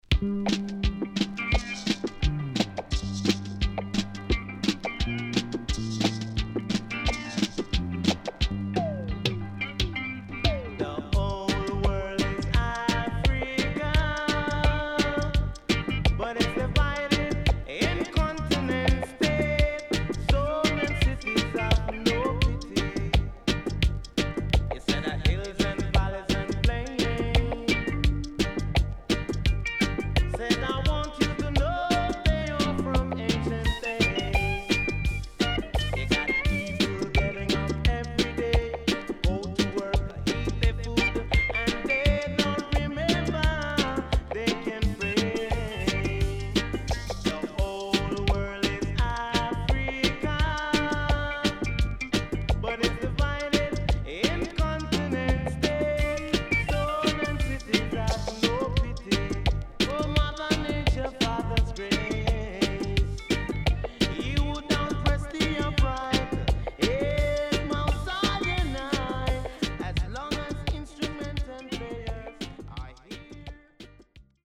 HOME > REISSUE USED [DANCEHALL]
SIDE A:少しチリノイズ入りますが良好です。